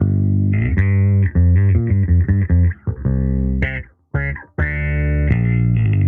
Index of /musicradar/sampled-funk-soul-samples/79bpm/Bass
SSF_JBassProc1_79G.wav